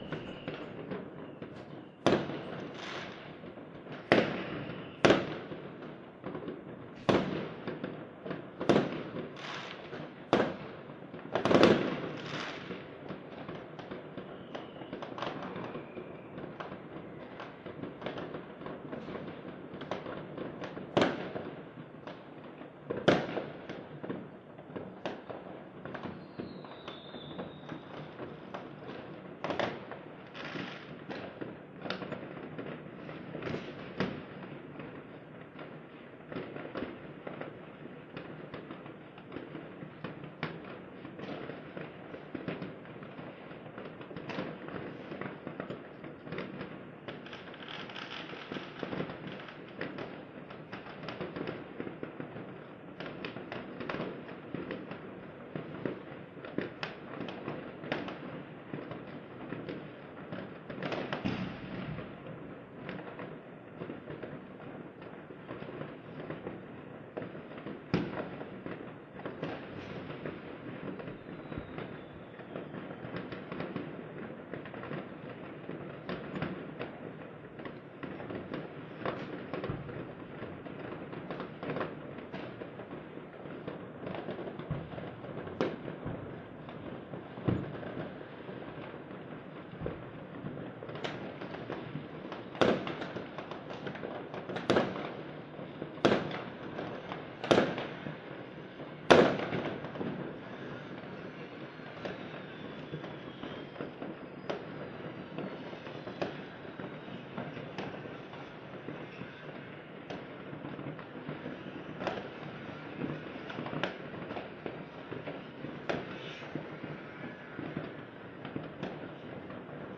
烟花 " 烟花电池 2
描述：大量的火箭和消防喷泉，响亮的哨声。
标签： 鞭炮 射击 繁荣 喷泉 烟火 一声哨响 烟花 爆竹 火箭 爆炸 火箭 新的一年
声道立体声